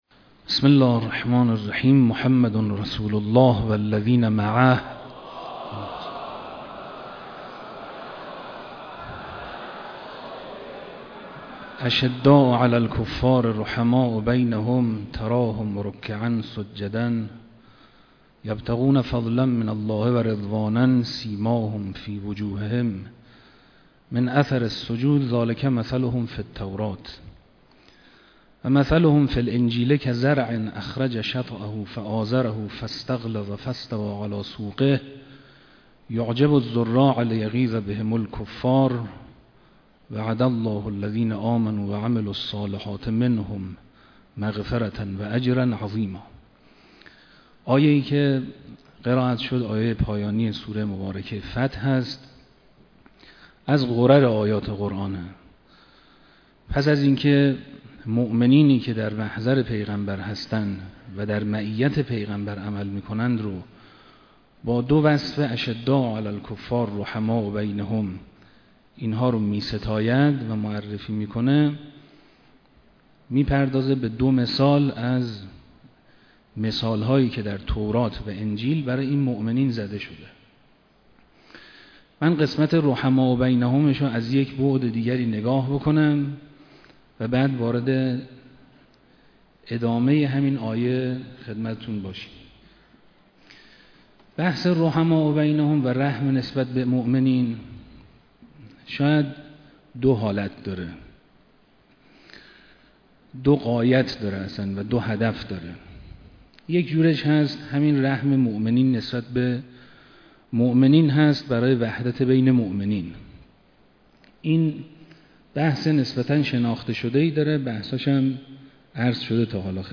مراسم عزاداری اربعین حسینی(ع) با حضور پرشور هیأت‌های دانشجویی
مراسم عزاداری اربعین حسینی(ع) در حضور رهبر معظم انقلاب اسلامی برگزار شد
سخنرانی